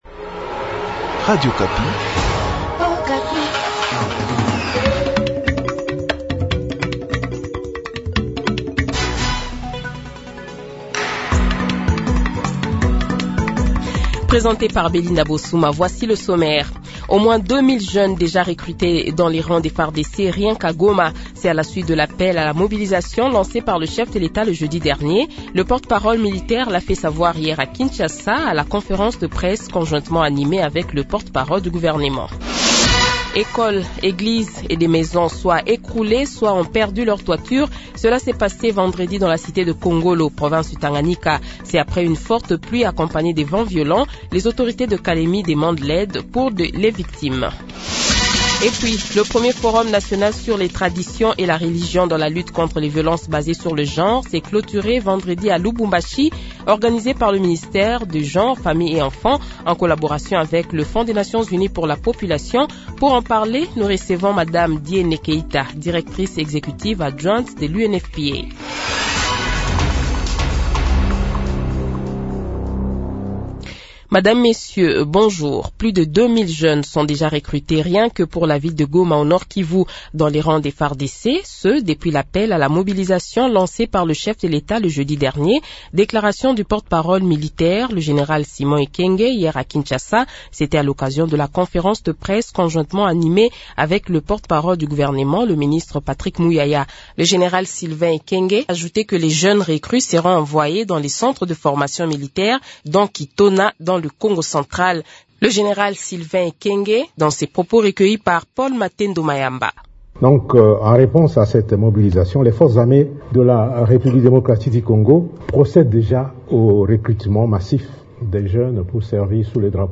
Journal Matin | Radio Okapi